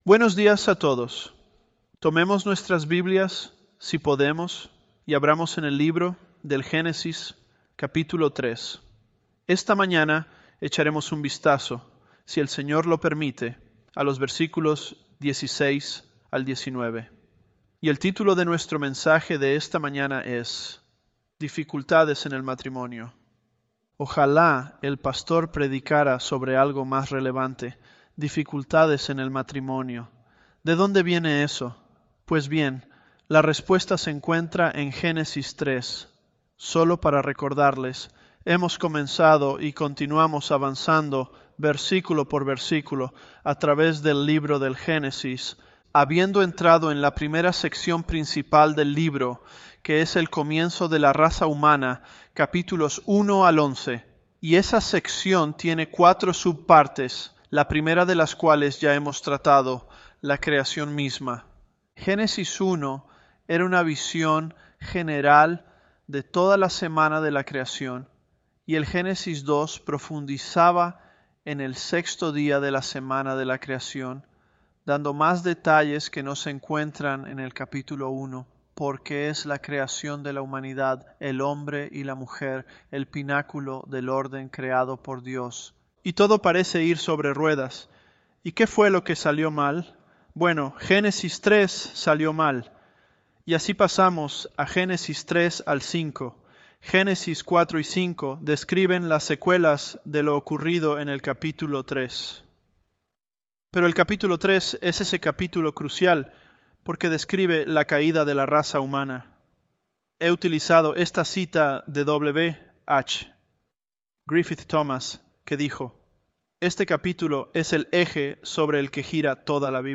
ElevenLabs_Genesis-Spanish014.mp3